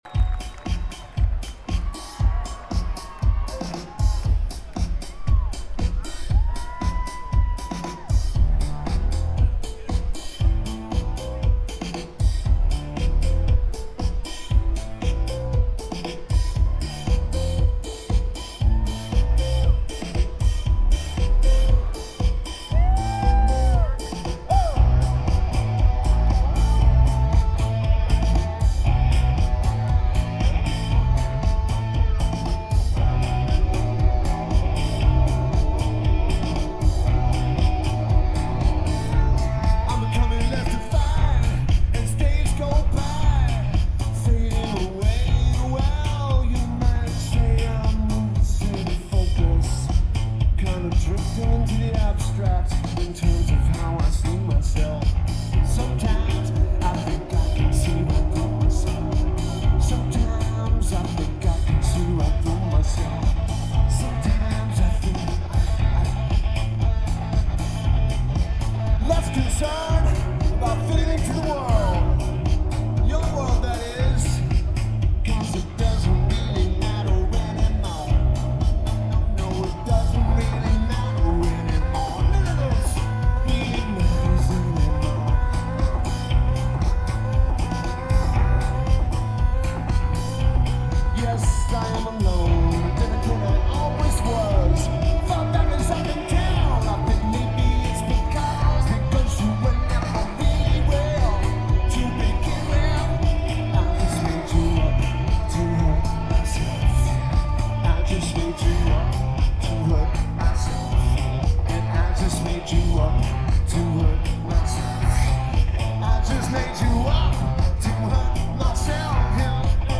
Red Hat Amphitheater
Lineage: Audio - AUD (AT853's (4.7k mod) > Tascam DR-05X)